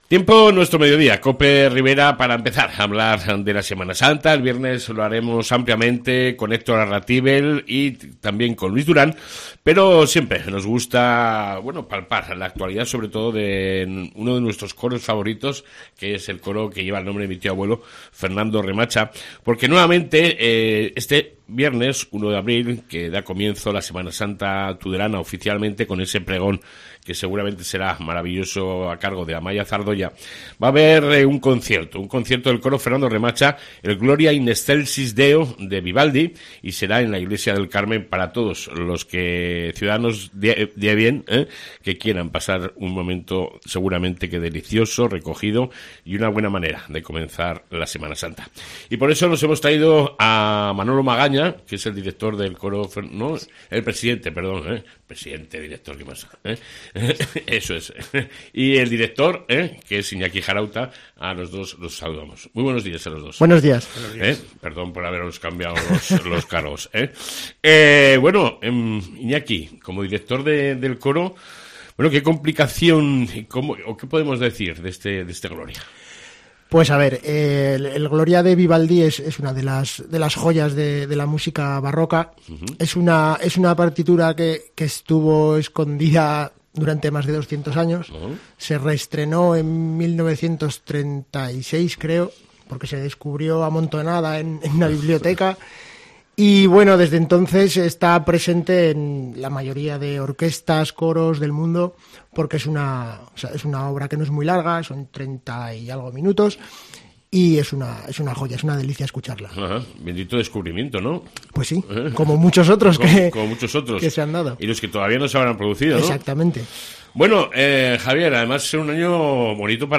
Concierto de Semana Santa con el Coro Fernando Remacha